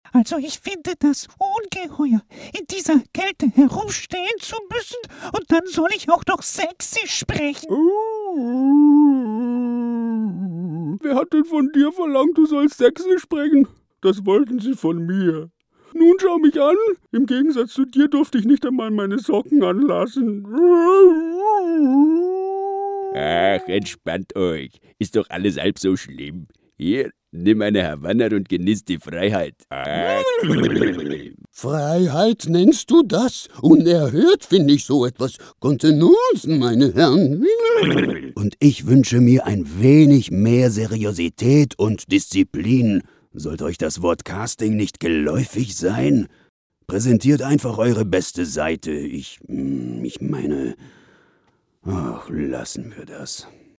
Sprechprobe: eLearning (Muttersprache):
voice over artist: german, brasilian, portuguese.